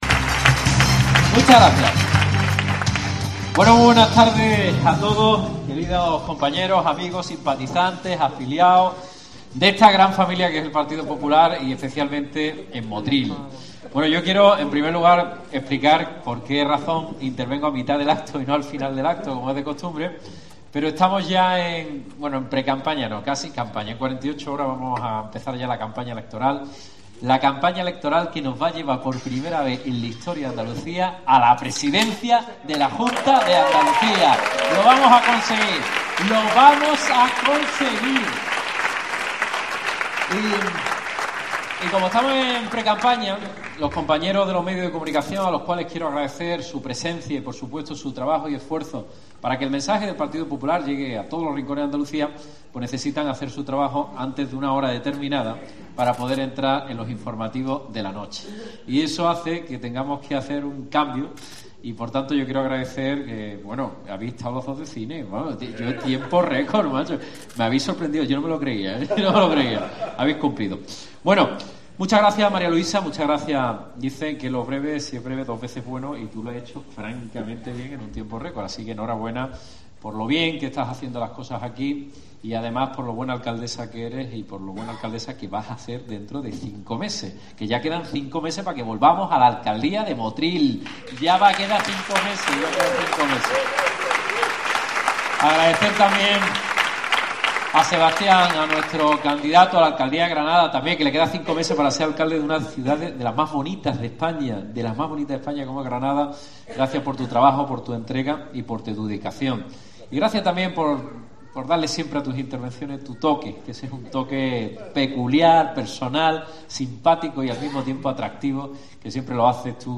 Mitín en Motril de JuanMa Moreno, candidato al Parlamento Andalúz por el PP para el 2 de diciembre